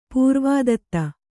♪ pūrvādatta